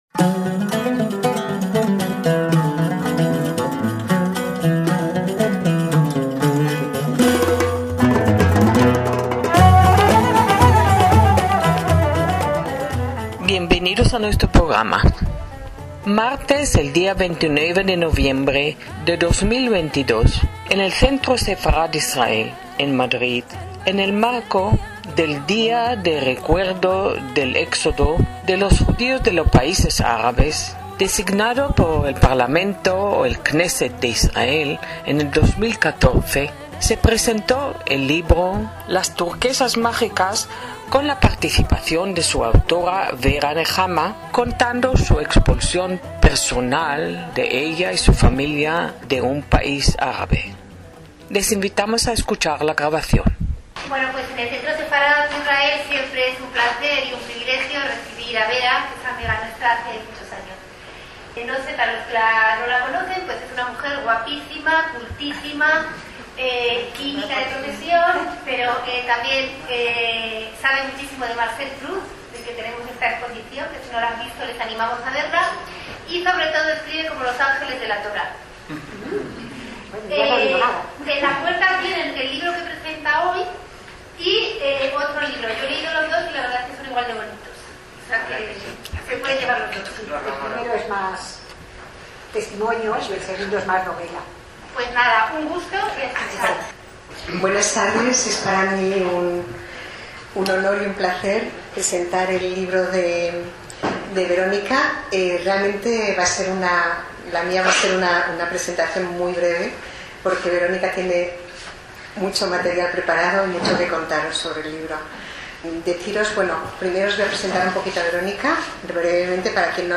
Presentación del libro «Las turquesas mágicas»
ACTOS EN DIRECTO